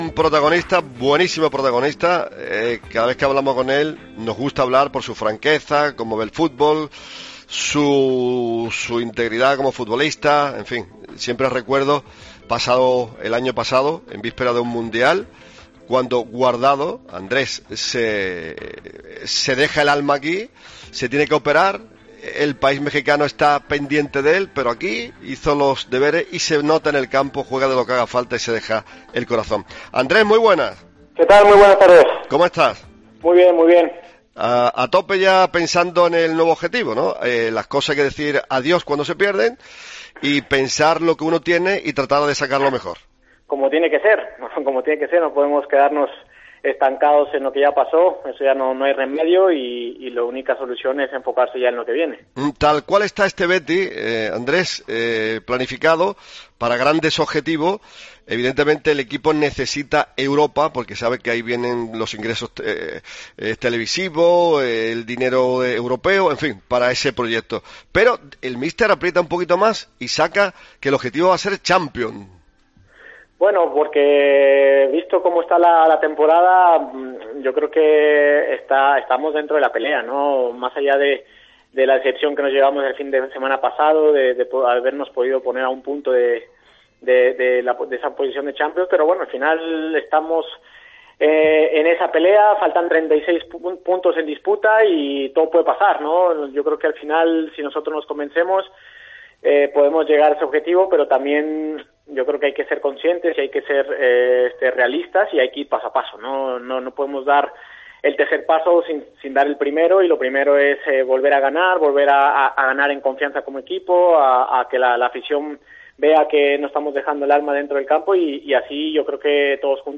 Entrevista a Andrés Guardado en Deportes Cope Más Sevilla